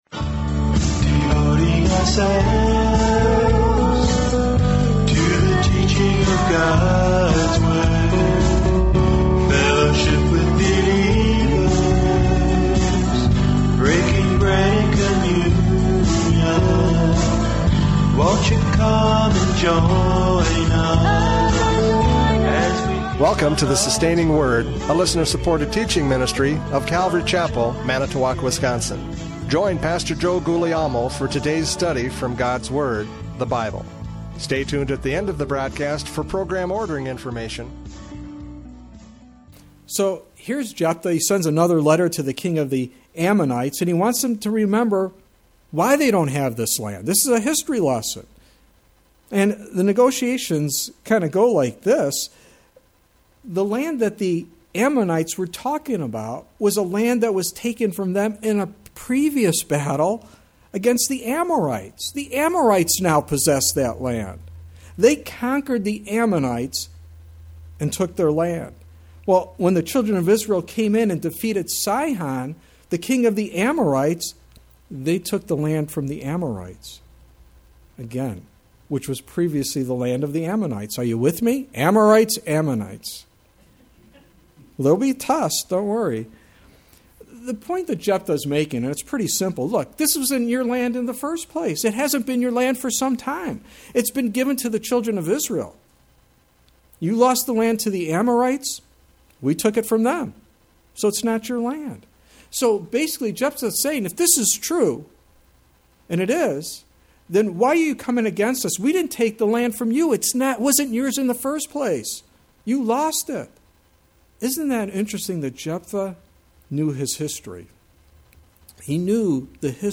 Judges 11:12-28 Service Type: Radio Programs « Judges 11:12-28 Jephthah Judges!